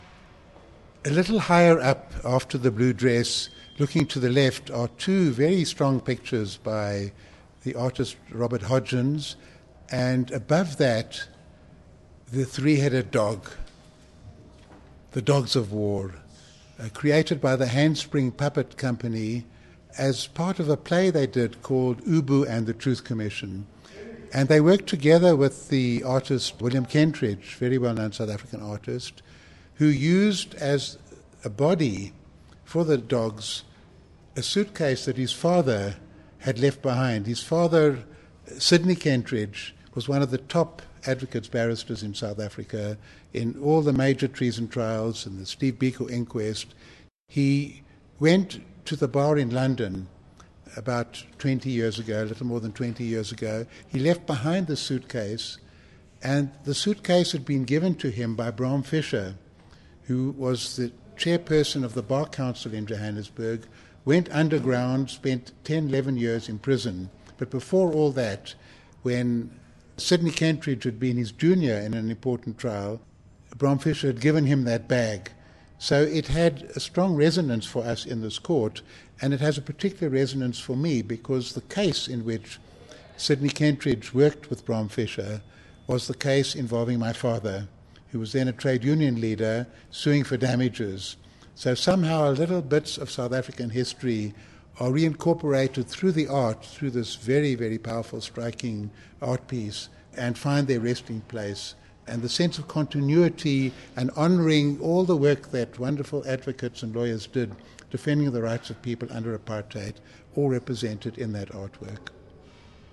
Albie Sachs speaking to Handspring Puppet Company's Dogs of War (audio)